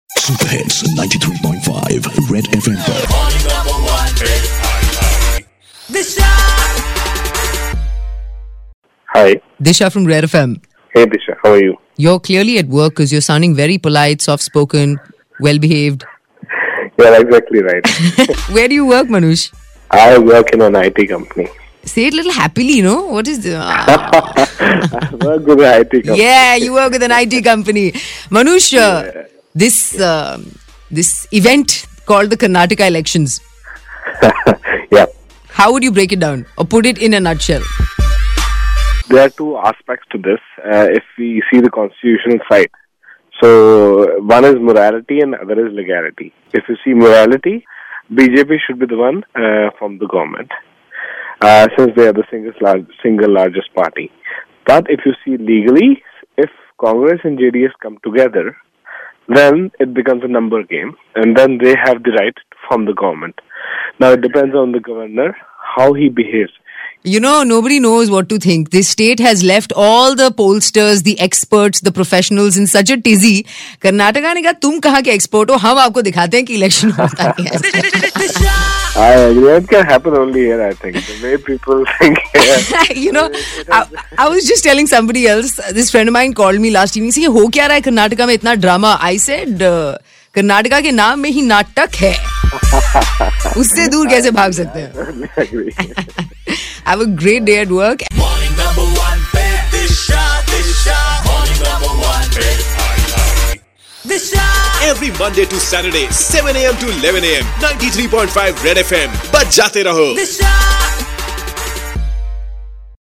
A listener who explains Karnataka Elections 2018